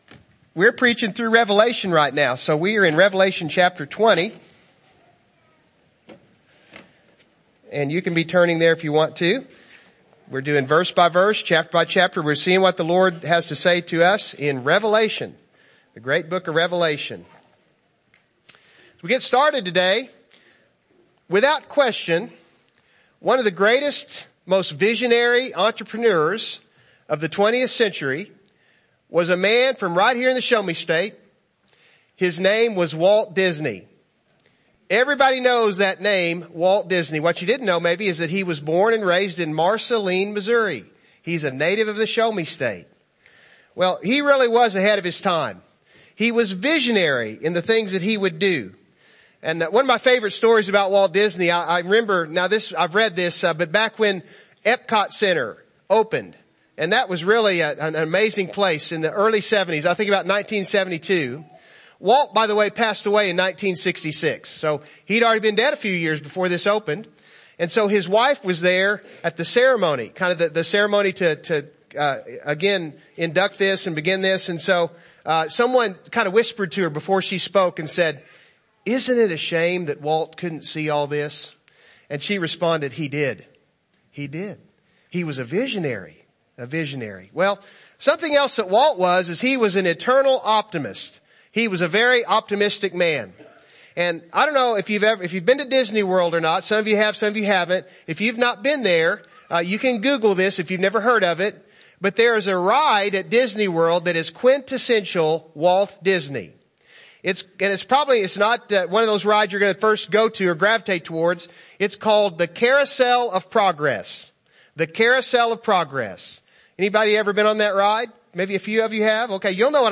In the End Service Type: Morning Service « Psalms